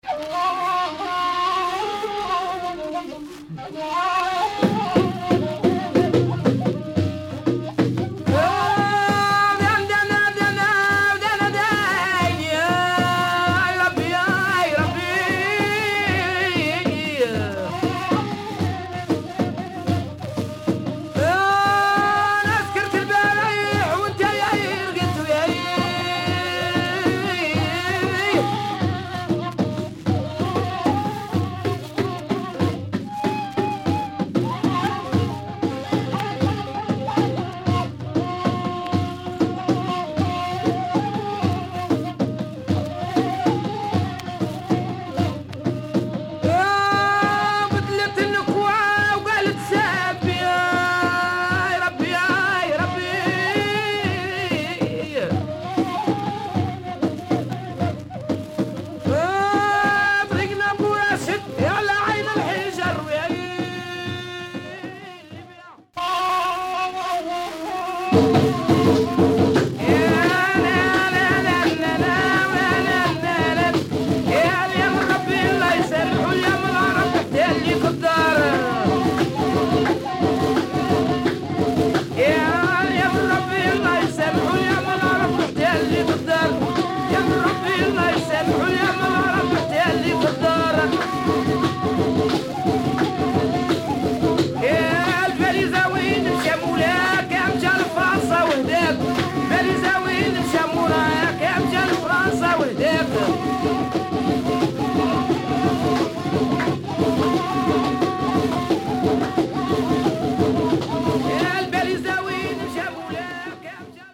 Very rare female raw chants from Algeria.